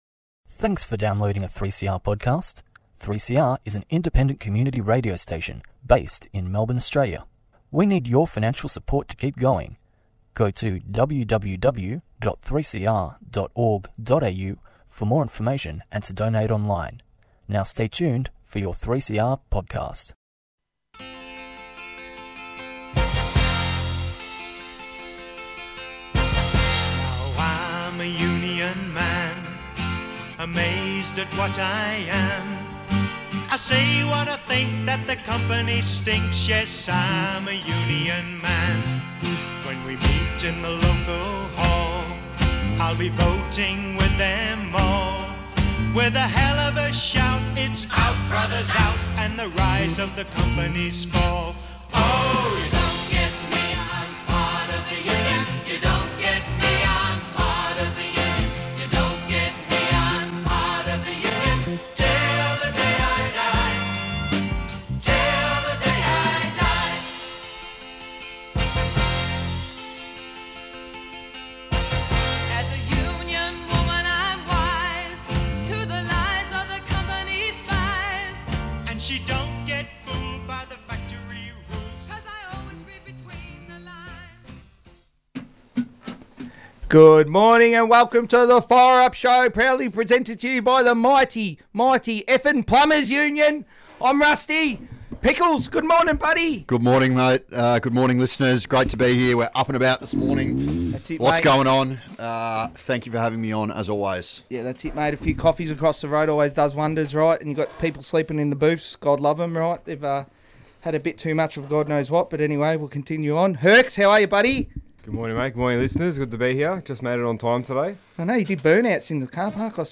Presenter Members of PTEU